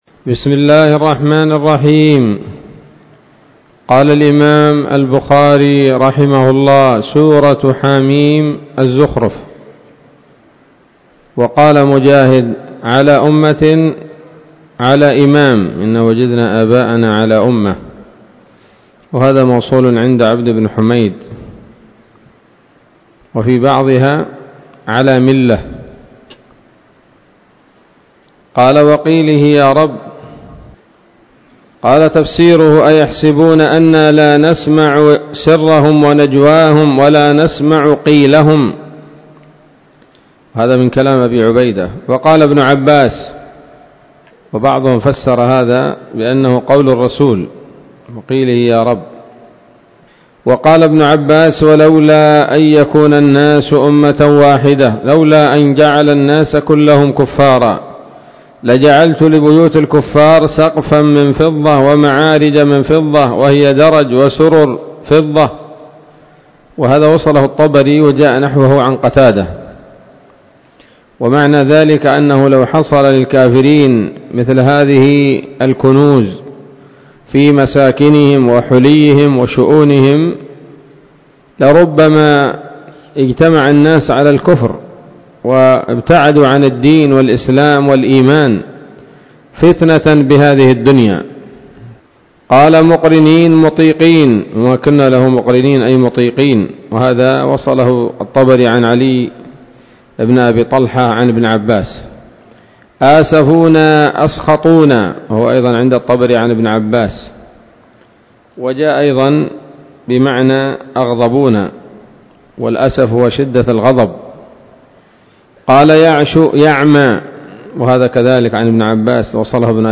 الدروس العلمية شروح الحديث صحيح الإمام البخاري كتاب التفسير من صحيح البخاري
الدرس الخامس والعشرون بعد المائتين من كتاب التفسير من صحيح الإمام البخاري